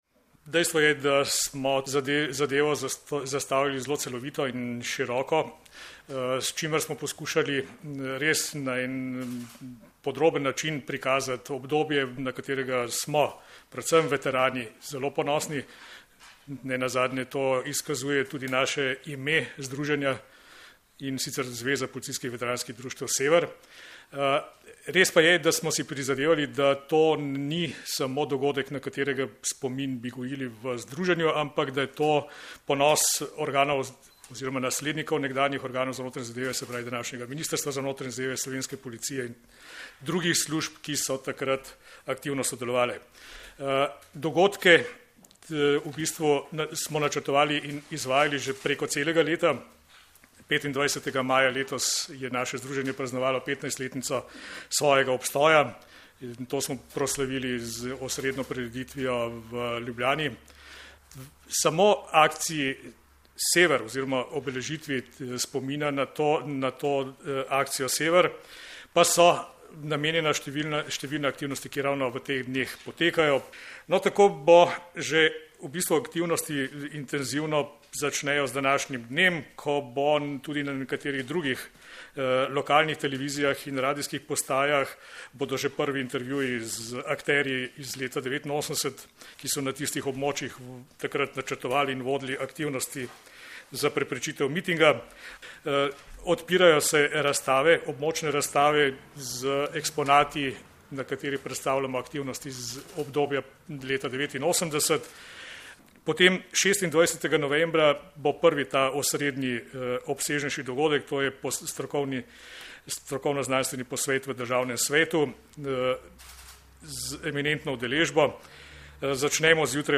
Pred dvema desetletjema, leta 1989, so takratni organi za notranje zadeve z akcijo Sever zaščitili demokratične procese v Sloveniji in na ta način omogočili demokratične volitve ter nastanek samostojne in neodvisne države Slovenije. Predstavniki Združenja Sever so na današnji novinarski konferenci podrobneje predstavili aktivnosti, s katerimi bomo letos obeležili 20. obletnico akcije Sever.